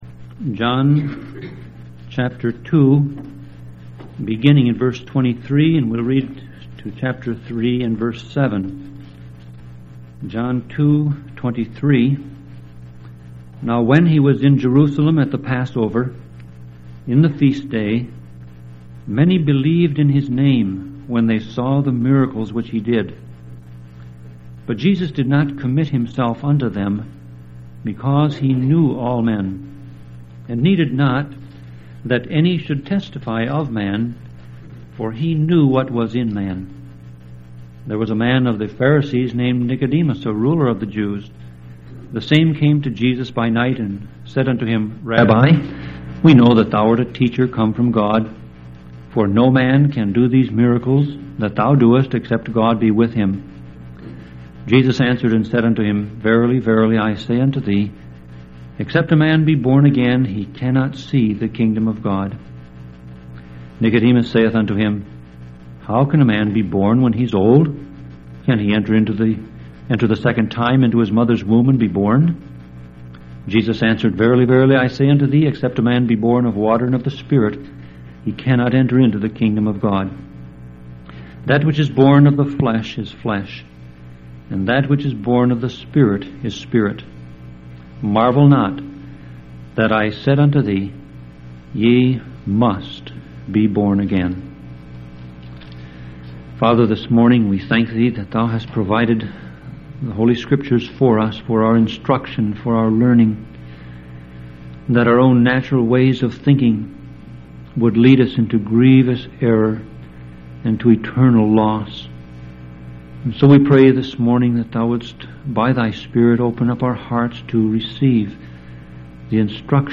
Sermon Audio Passage: John 2:23-3:7 Service Type